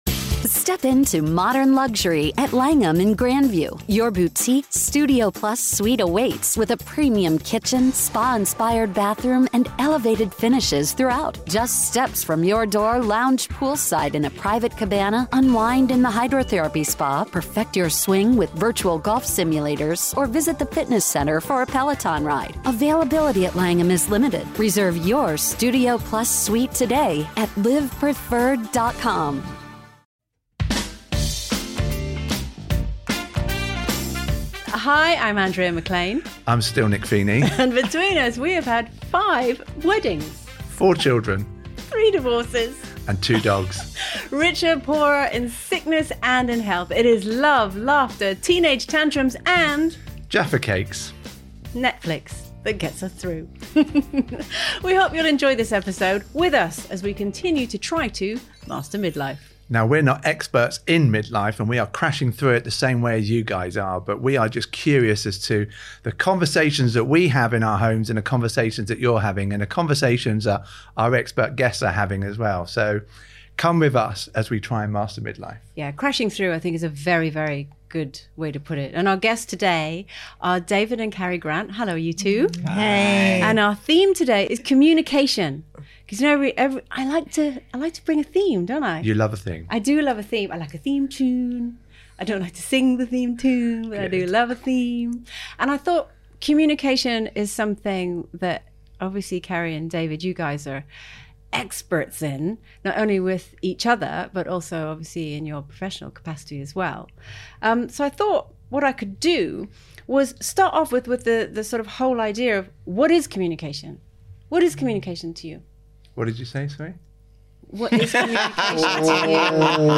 Our guests today are David and Carrie Grant, who have so much to share on today’s theme of communication.